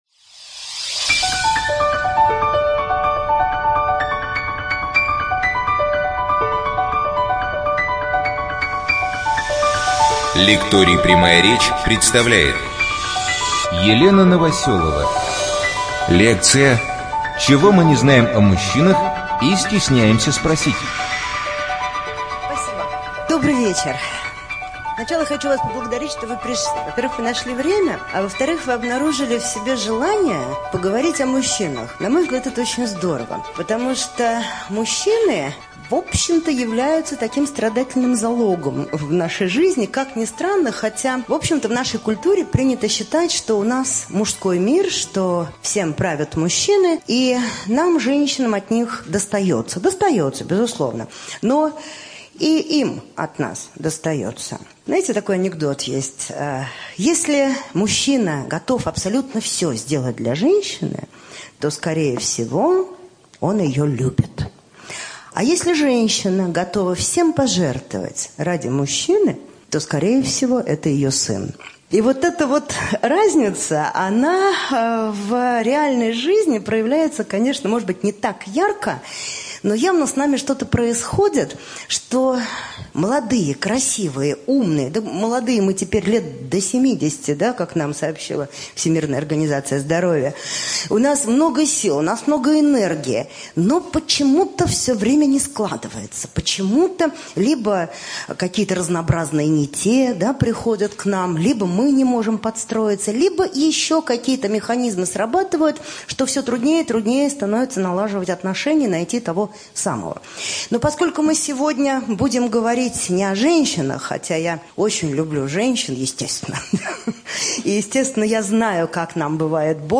ЧитаетАвтор
Студия звукозаписиЛекторий "Прямая речь"